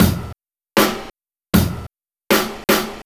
Track 11 - Kick Snare Beat 03.wav